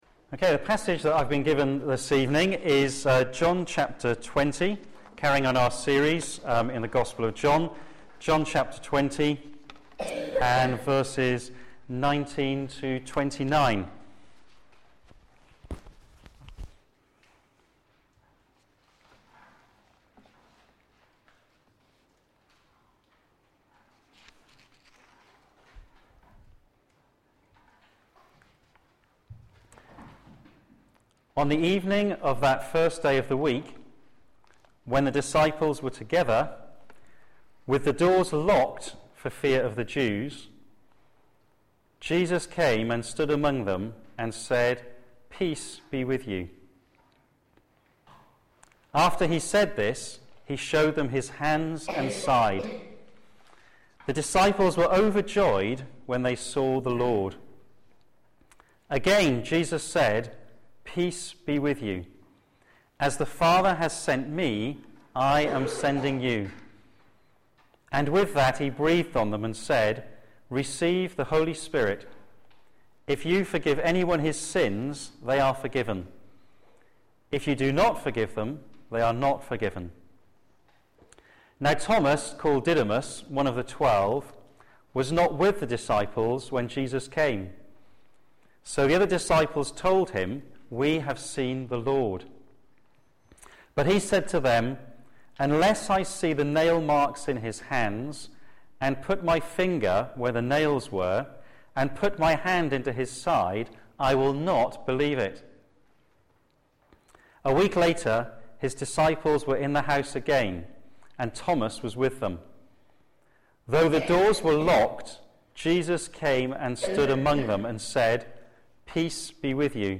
p.m. Service
Series: John on Jesus Theme: The risen Christ appears to His disciples Sermon